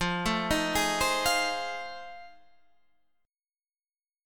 F 7th Sharp 9th